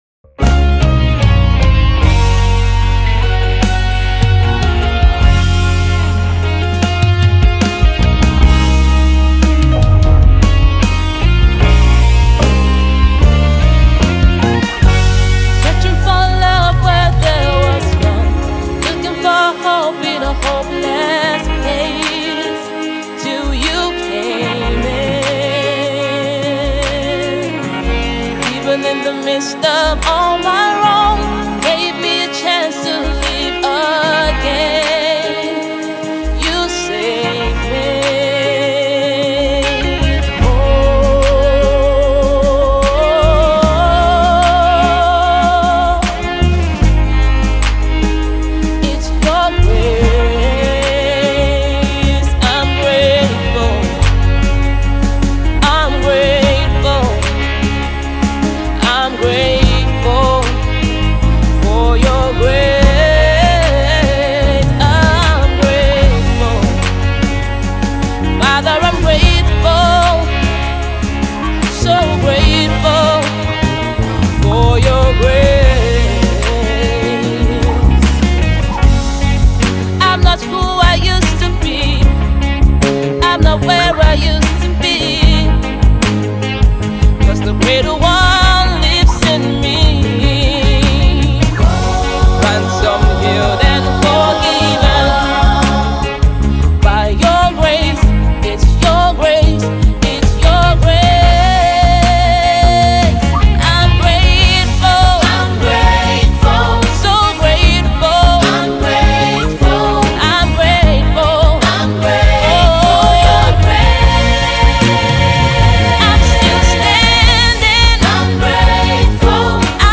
She is a singer with a huge passion for gospel music.